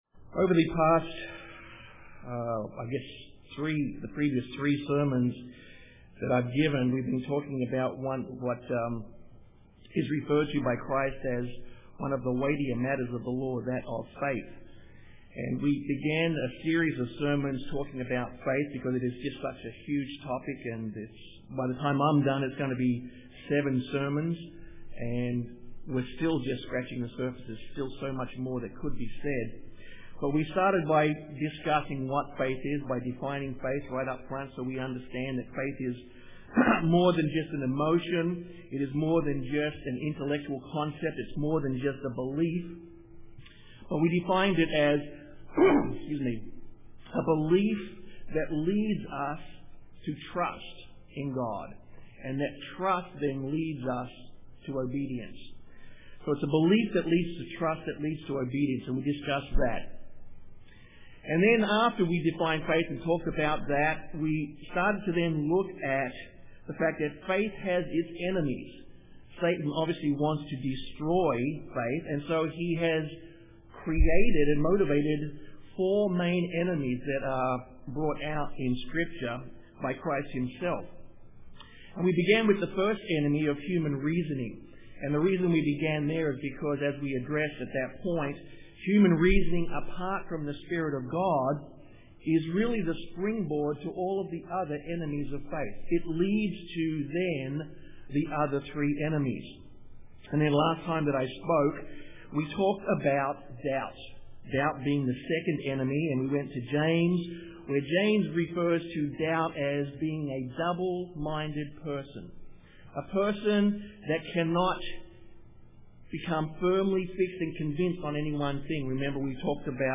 Given in San Antonio, TX
UCG Sermon Studying the bible?